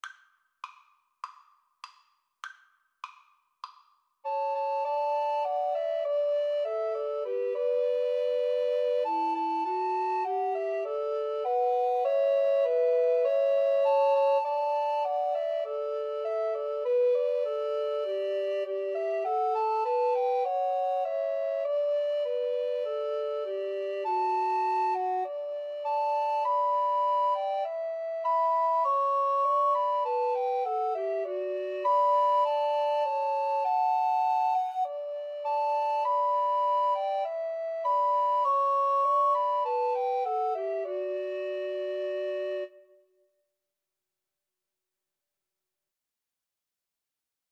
Alto RecorderTenor RecorderBass Recorder
4/4 (View more 4/4 Music)